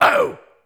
OH-.wav